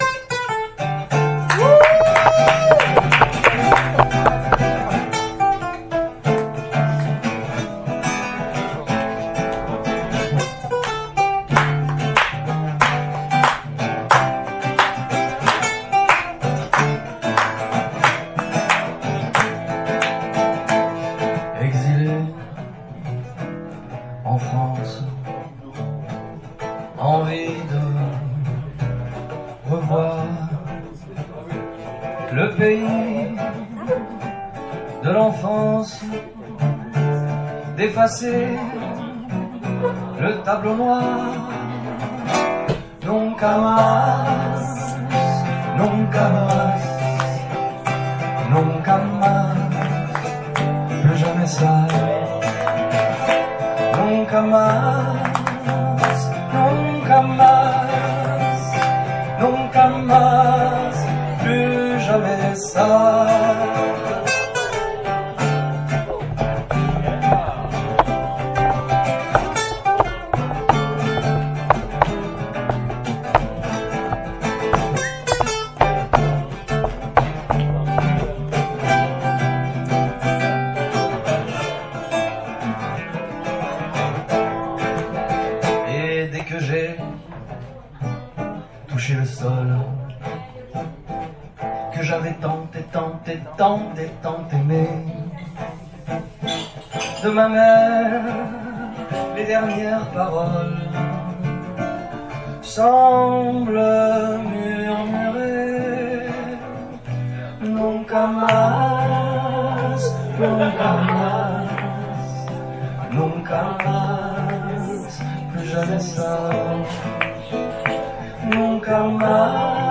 Chez Adel, 3 novembre 2016